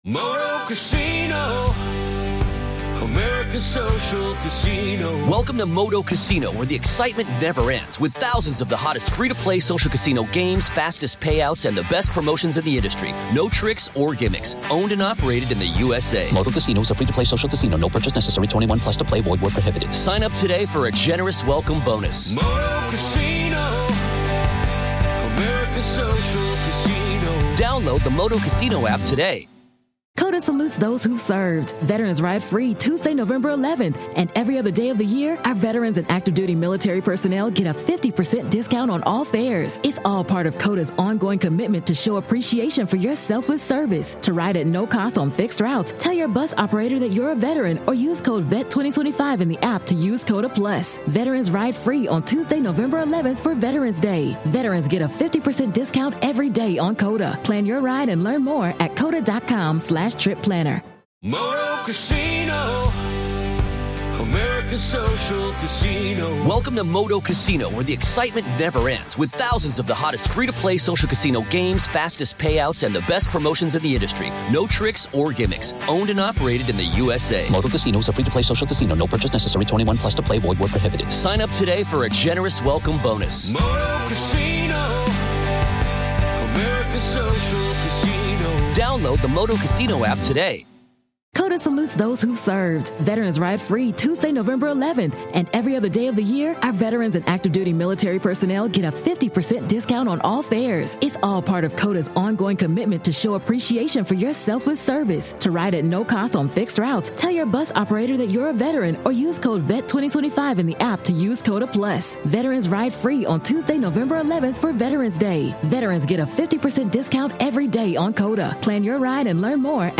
sits down with legal expert